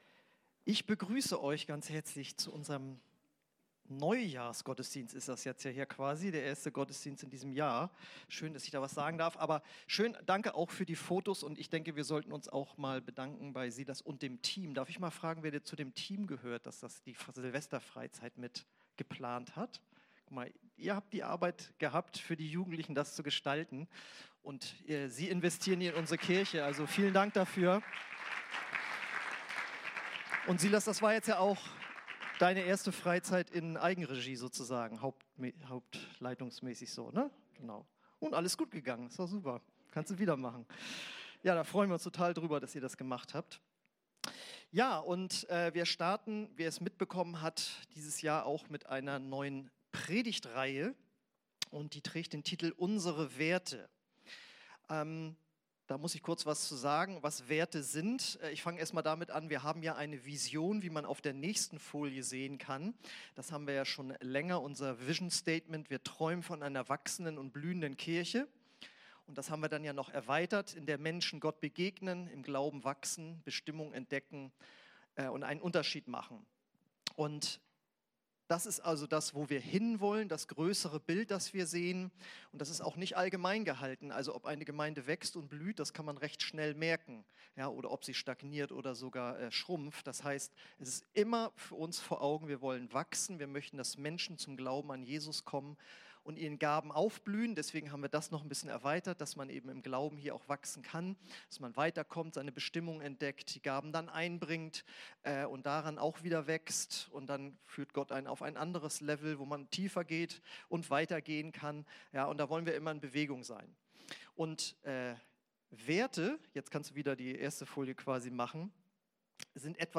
Predigten (v1) – OASIS Kirche
Aus der Predigtreihe: "Unsere Werte"